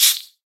Minecraft Version Minecraft Version 1.21.4 Latest Release | Latest Snapshot 1.21.4 / assets / minecraft / sounds / mob / silverfish / hit2.ogg Compare With Compare With Latest Release | Latest Snapshot